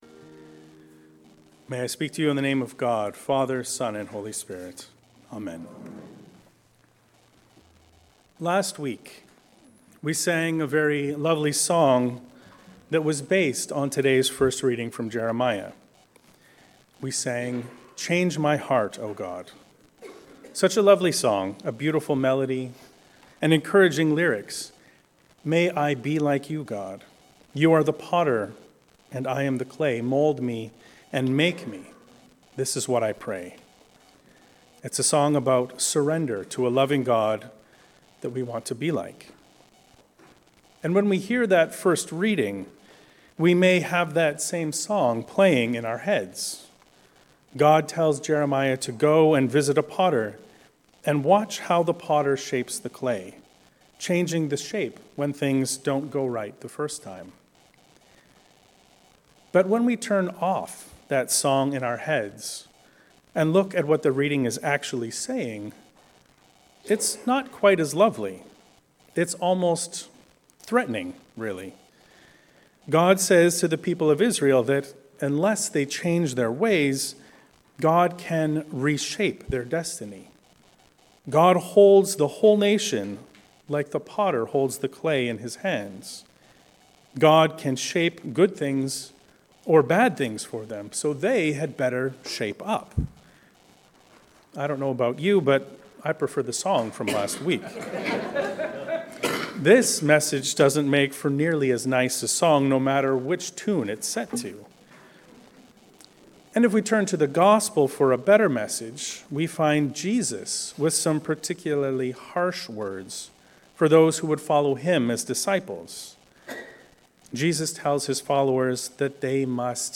The Demands of Discipleship. A sermon on Luke 14